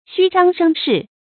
xū zhāng shēng shì
虚张声势发音
成语正音势，不能读作“sì”。